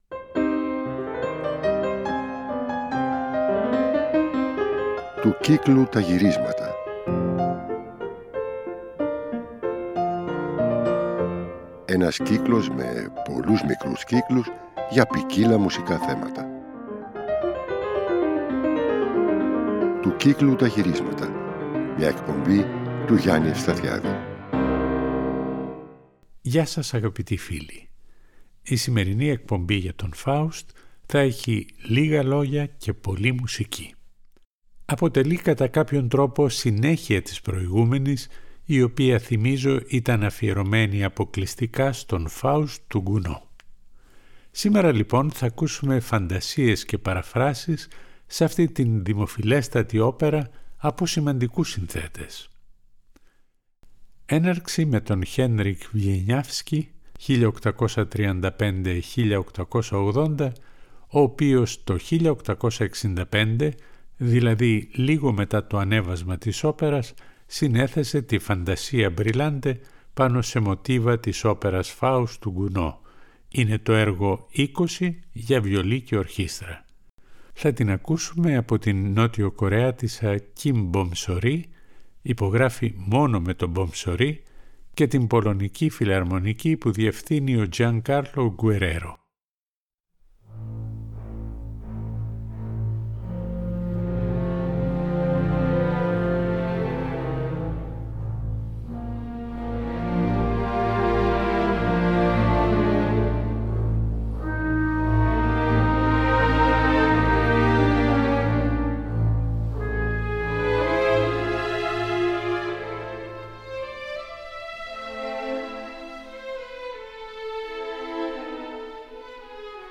Θα ακουστούν φαντασίες πάνω σε θέματα της όπερας